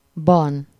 Ääntäminen
IPA : /ɪn/ US : IPA : [ɪn]